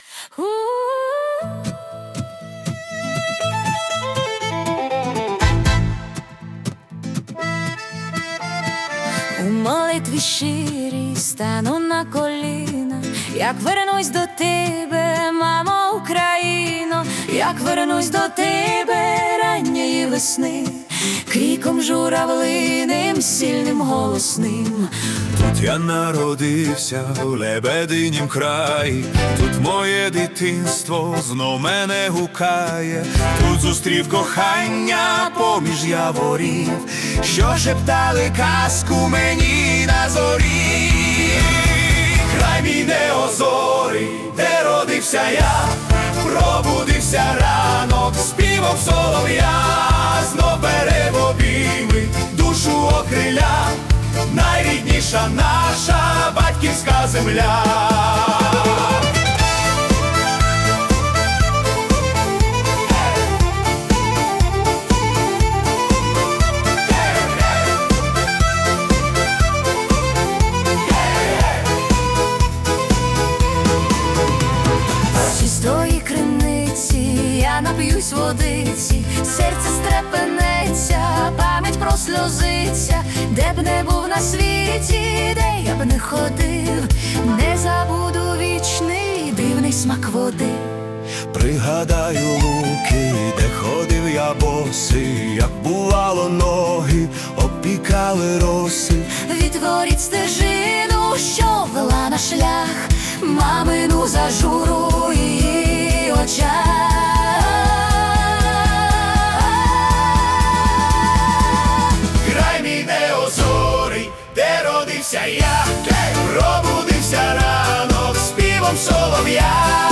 🎵 Жанр: Lyrical Ballad / Estrada
глибока та емоційна балада